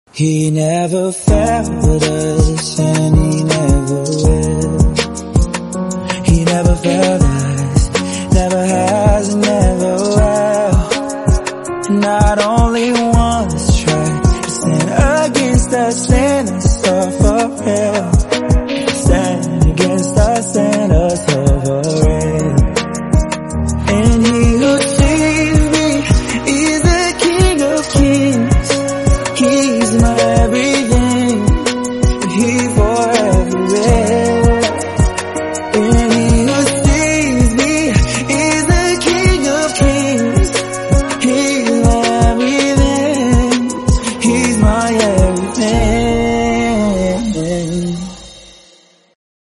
produced on my phone